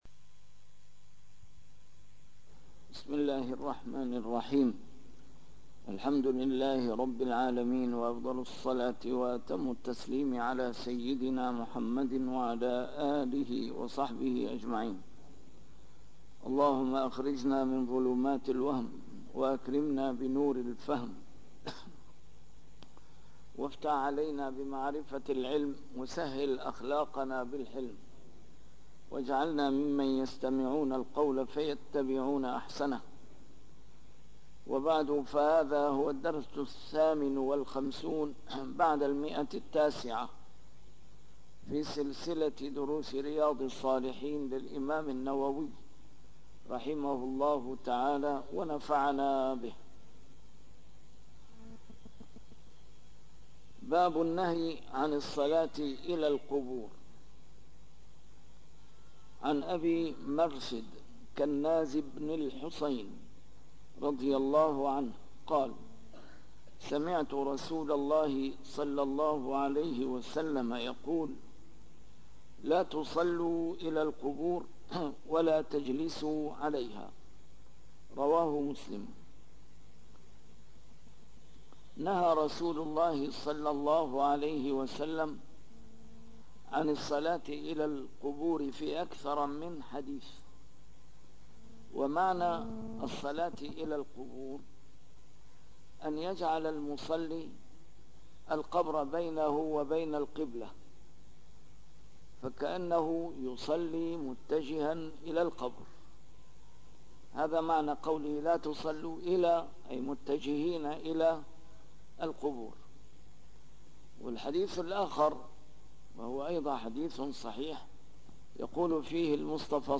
A MARTYR SCHOLAR: IMAM MUHAMMAD SAEED RAMADAN AL-BOUTI - الدروس العلمية - شرح كتاب رياض الصالحين - 958- شرح رياض الصالحين: النهي عن الصلاة إلى القبور - تحريم المرور بين يدي المصلي